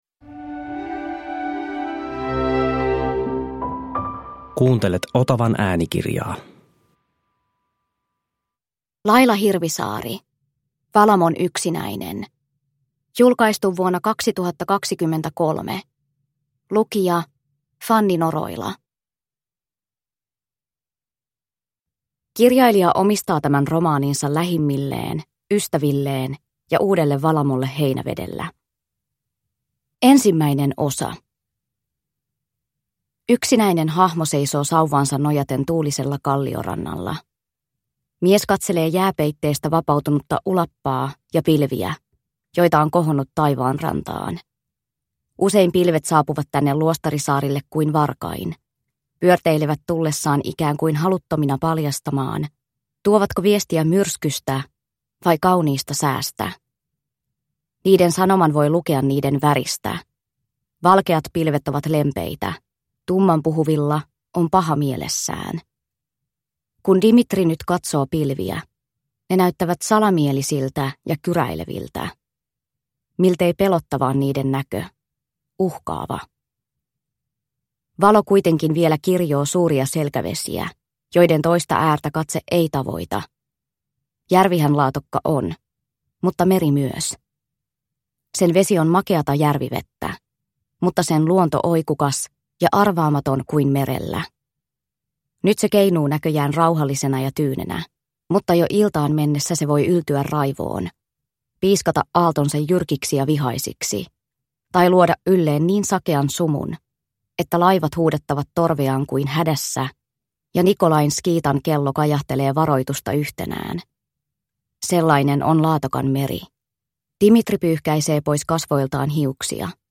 Valamon yksinäinen (ljudbok) av Laila Hirvisaari